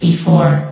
CitadelStationBot df15bbe0f0 [MIRROR] New & Fixed AI VOX Sound Files ( #6003 ) ...
before.ogg